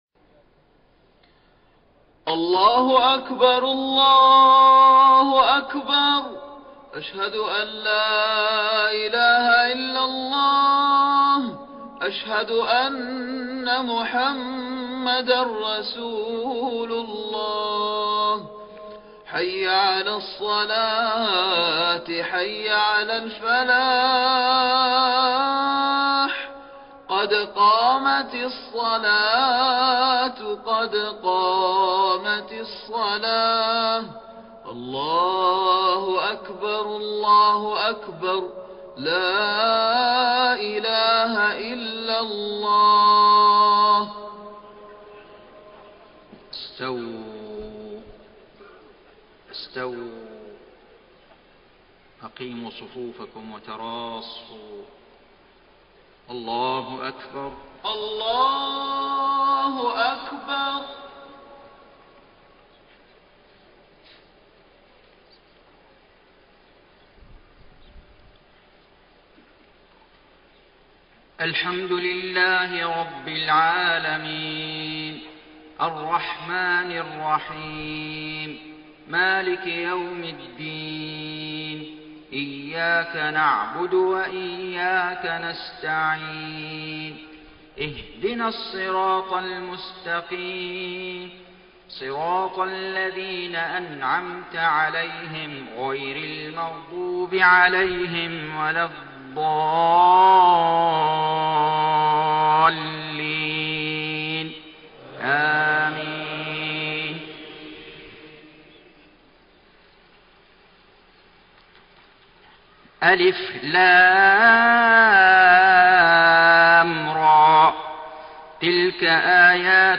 صلاة الفجر 17 رجب 1433هـ فواتح سورة يونس 1-14 > 1433 🕋 > الفروض - تلاوات الحرمين